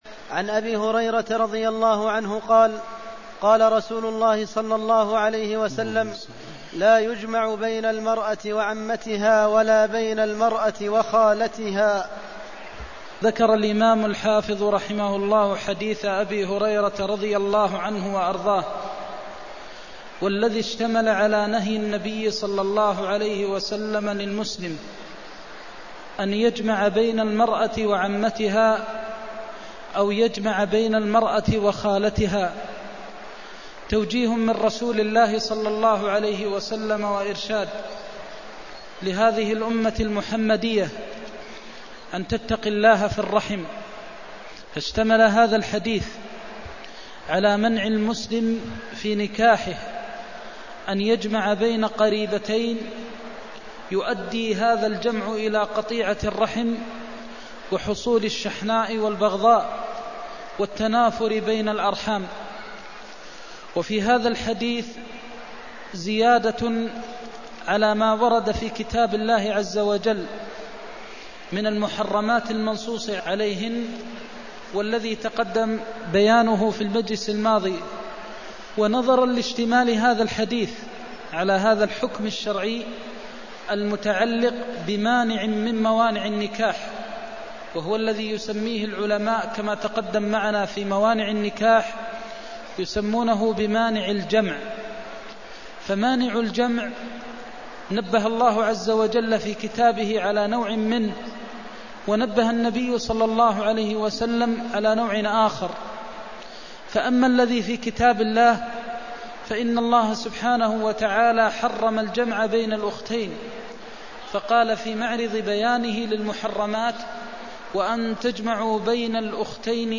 المكان: المسجد النبوي الشيخ: فضيلة الشيخ د. محمد بن محمد المختار فضيلة الشيخ د. محمد بن محمد المختار تحريم الجمع بين المرأة وعمتها أو خالتها (288)